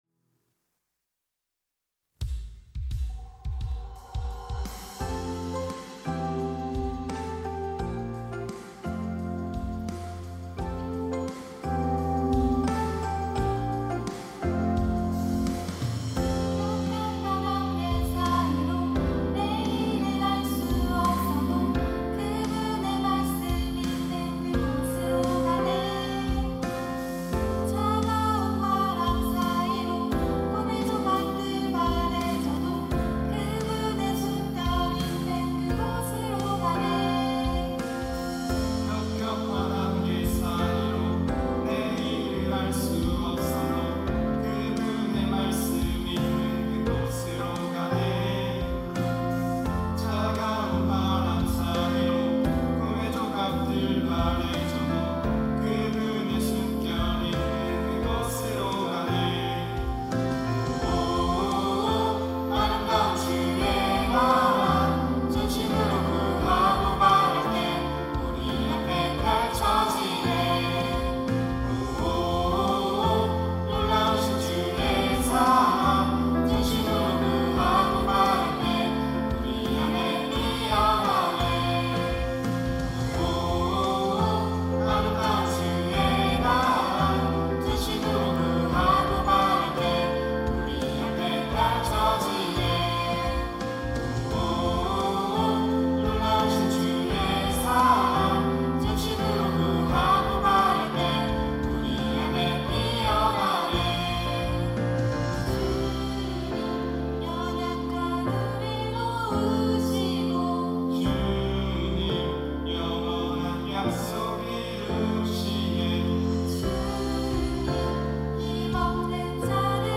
특송과 특주 - 주의 나라 펼쳐지네